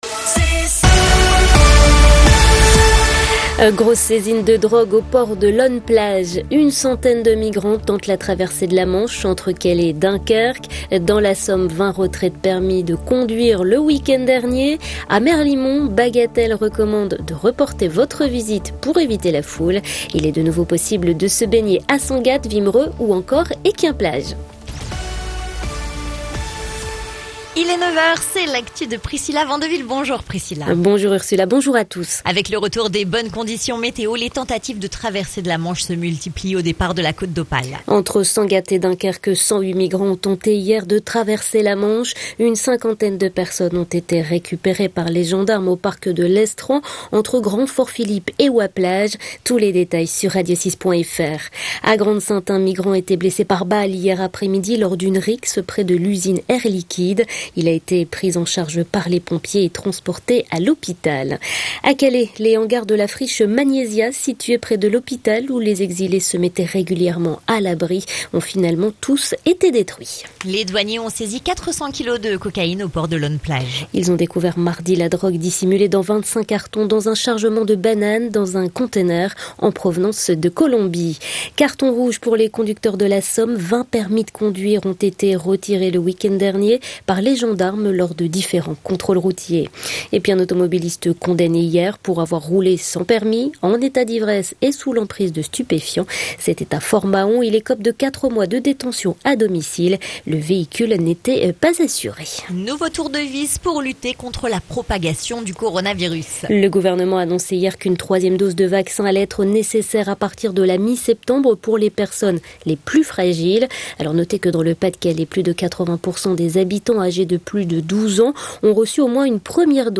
Le journal du jeudi 12 août